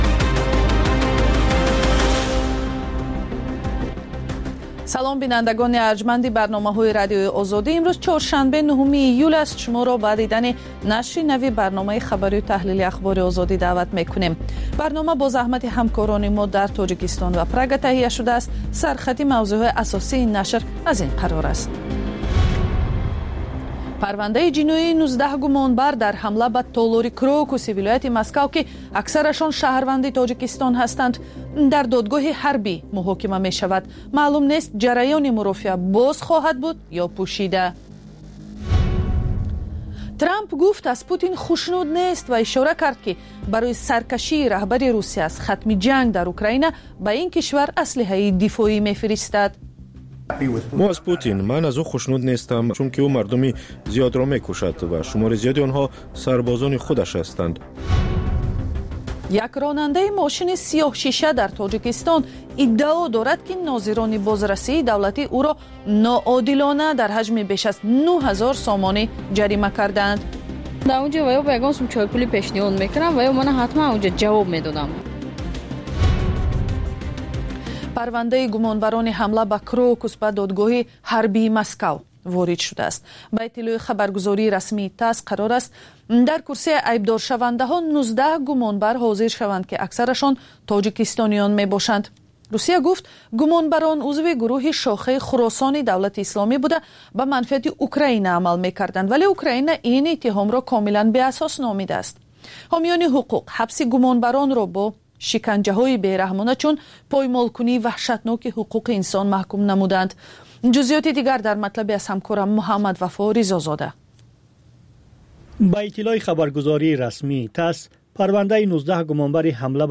Пахши зинда